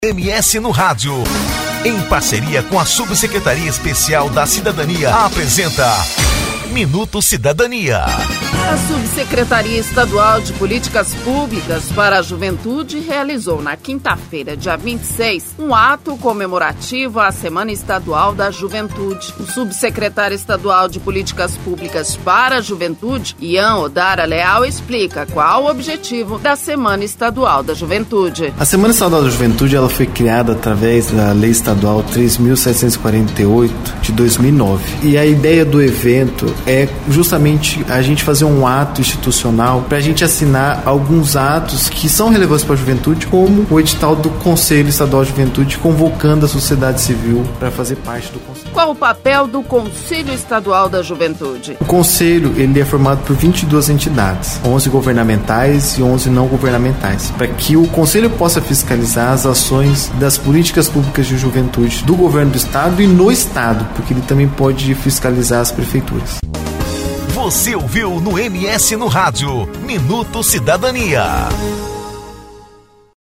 Campo Grande (MS) - A Subsecretaria Estadual de Políticas Públicas para Juventude realizou na quinta-feira (26), um ato comemorativo a “Semana Estadual da Juventude”. O Subsecretário Estadual de Políticas Públicas para Juventude, Ian Odara Leal, explica qual o objetivo da Semana Estadual da Juventude.